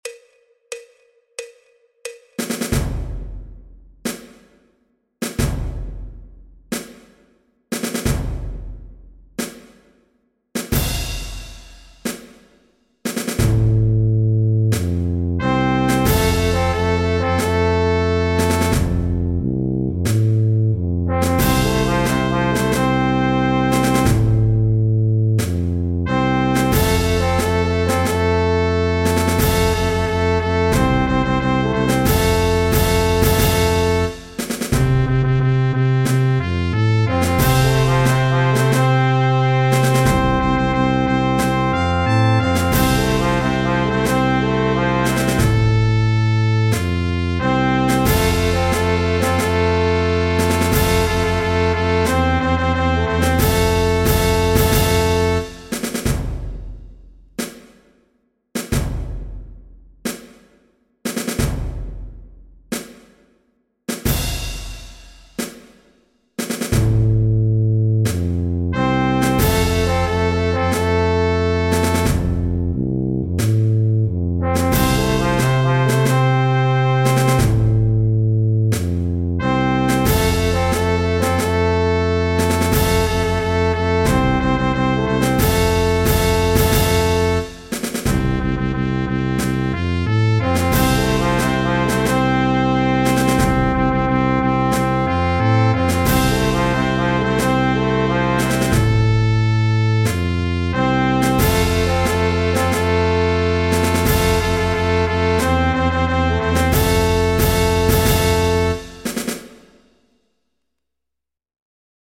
Marchas de Procesión
Tuba : Contrabajo en Fa Mayor (F)